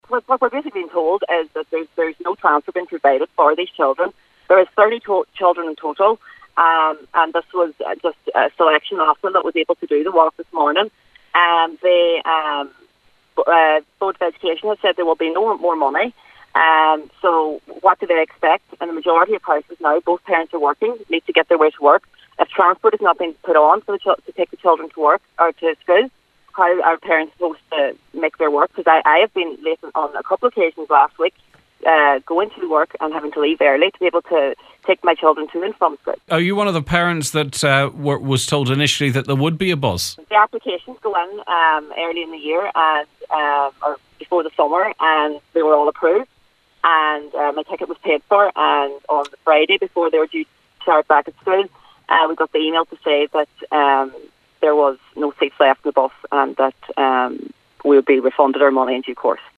Earlier today on the Nine til Noon Show